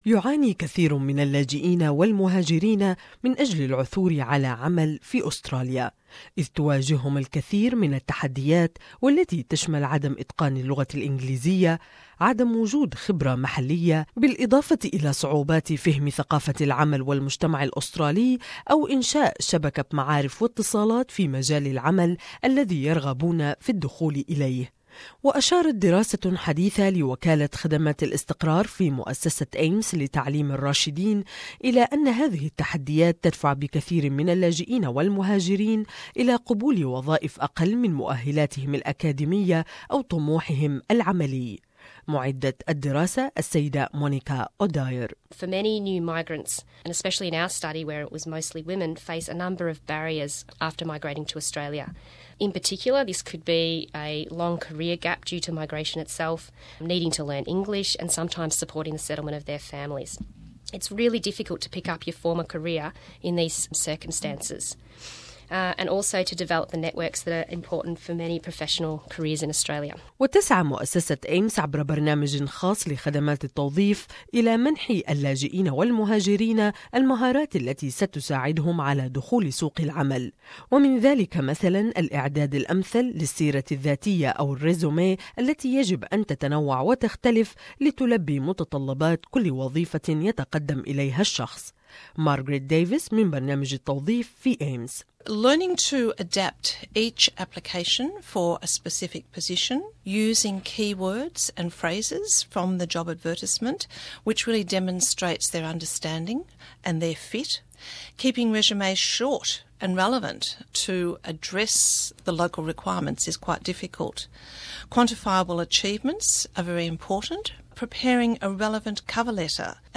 More in this report about services and help available for jobseekers.